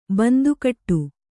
♪ bandu kaṭṭu